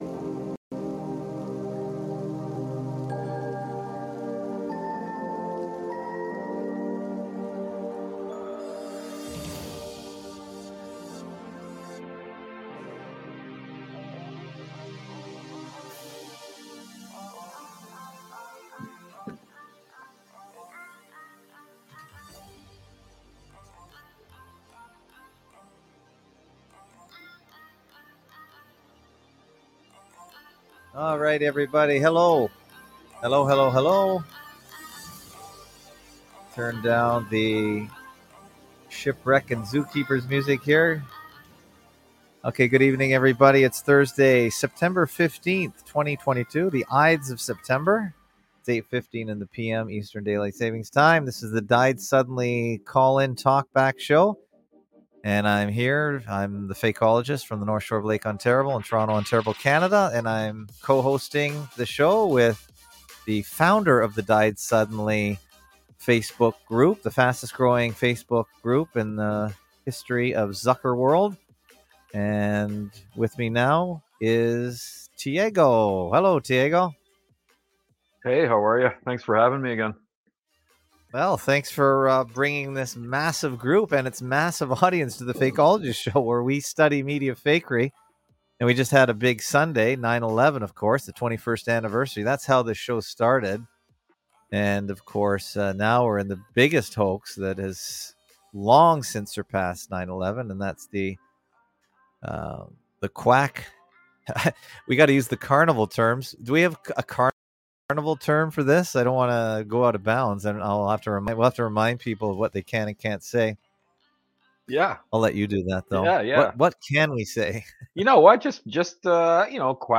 Fakeologist show Live Sun-Thu 830pm-900pm EDT